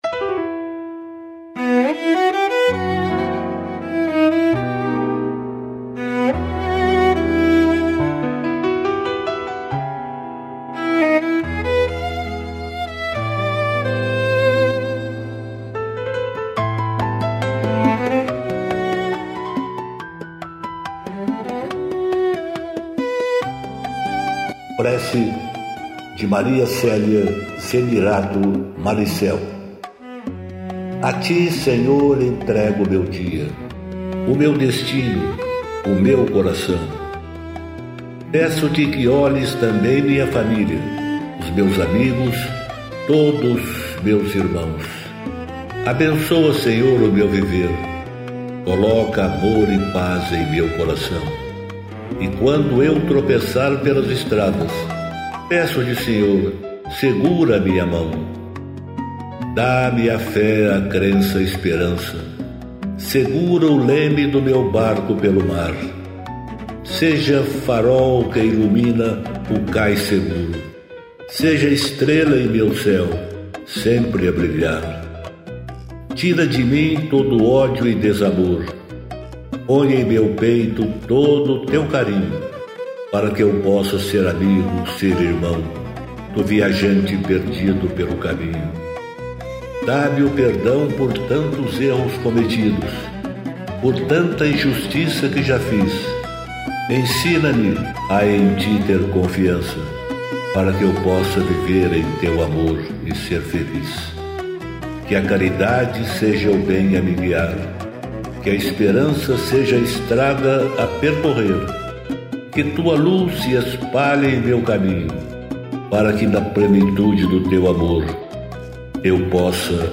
música e arranjo: IA